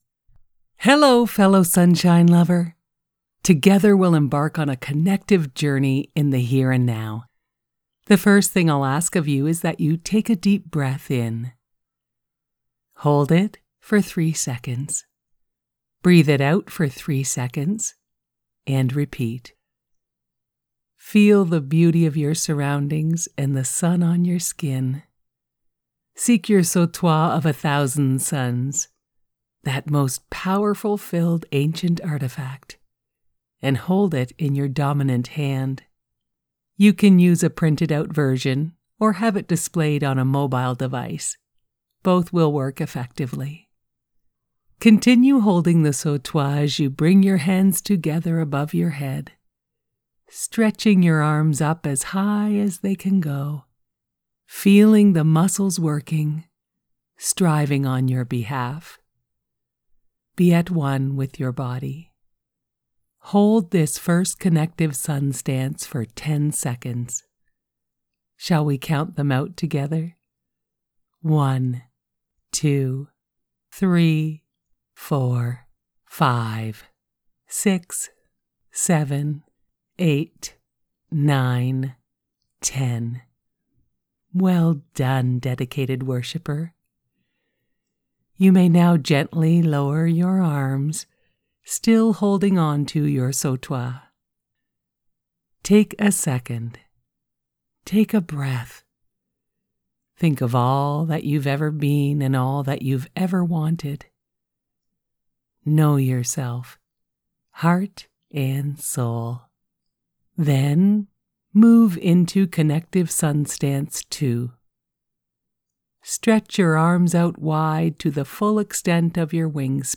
For one, you can perform several Connective Sun Stances, following my vocal instructions (you’ll find these attached below).